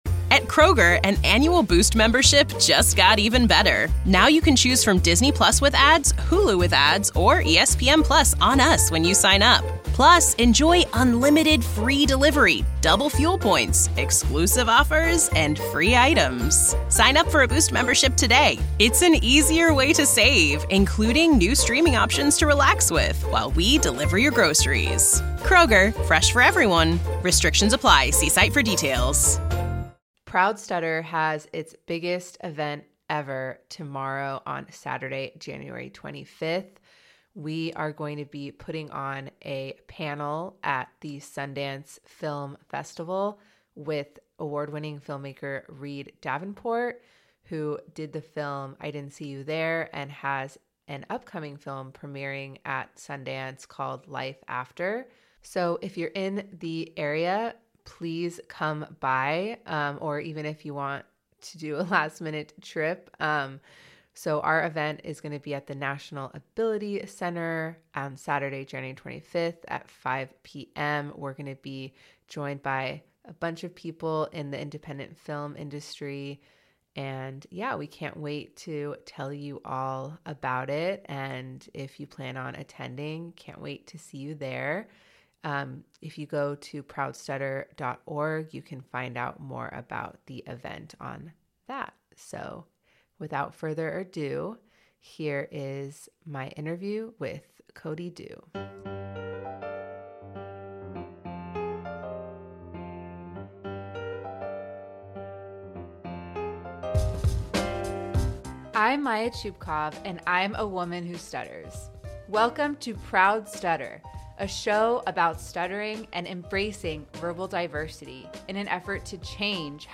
A podcast about changing how we understand and talk about stuttering, one conversation at a time.